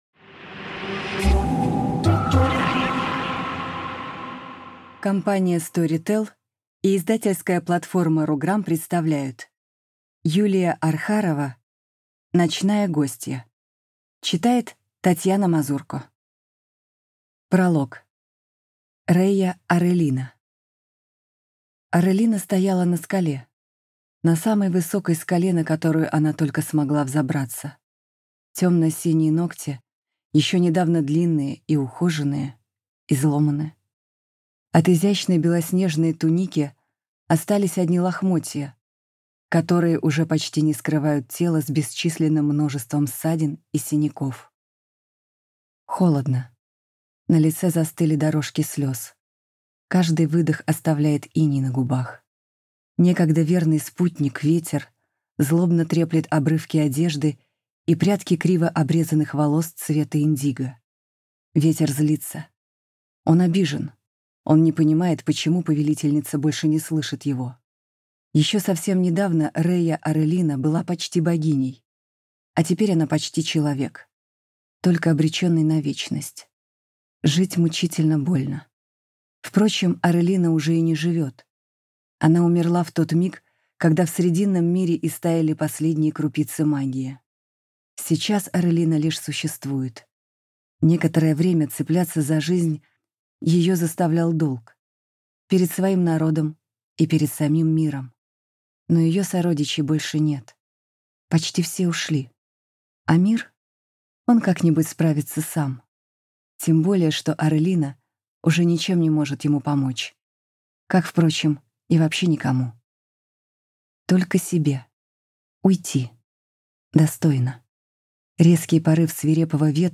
Аудиокнига Ночная гостья | Библиотека аудиокниг